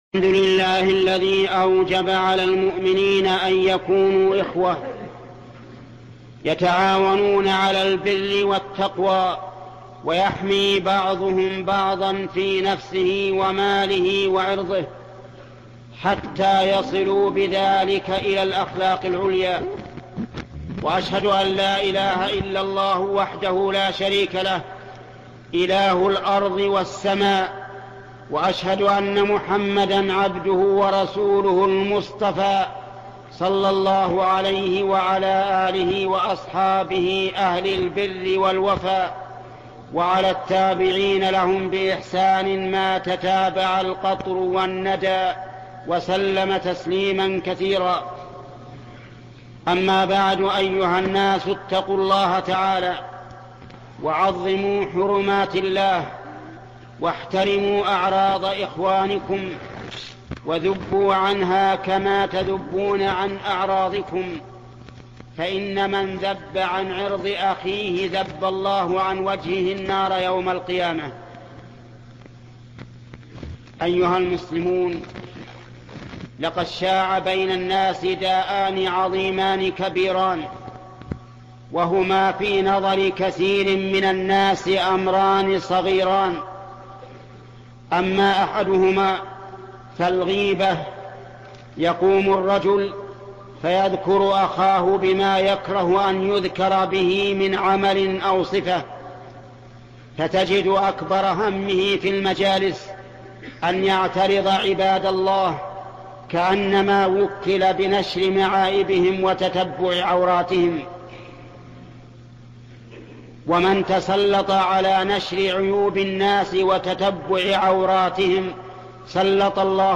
خطب الجمعة